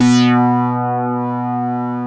OSCAR 8 B3.wav